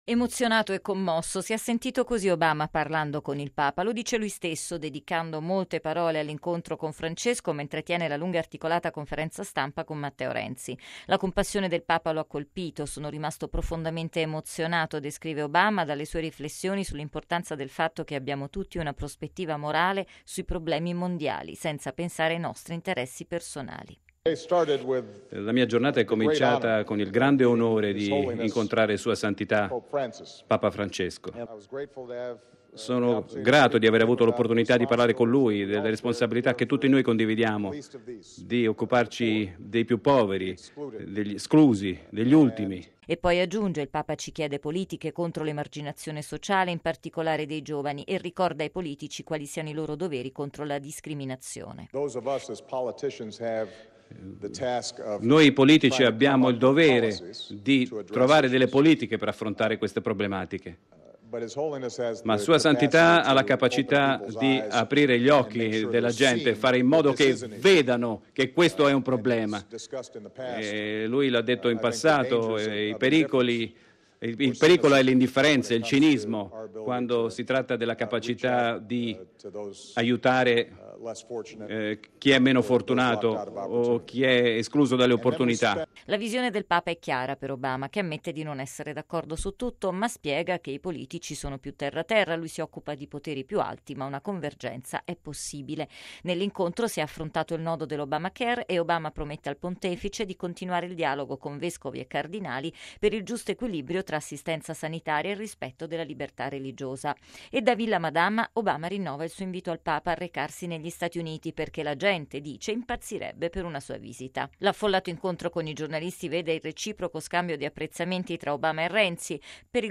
Lo dice lui stesso, dedicando molte parole all’incontro con Francesco, mentre tiene la lunga e articolata conferenza stampa con Renzi.